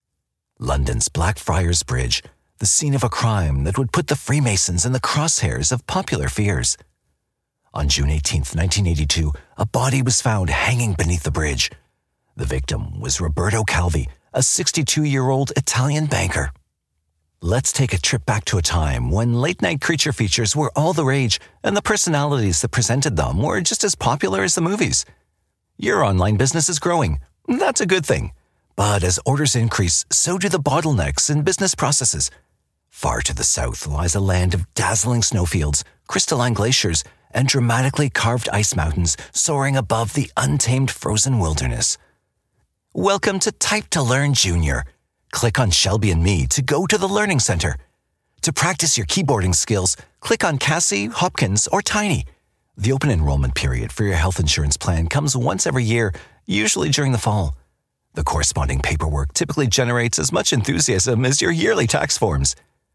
Narration demo
Known for my warm, resonant baritone, I provide clear, trusted narration for commercials, web videos, eLearning, corporate content, explainers, IVR, animated characters, video games, and more.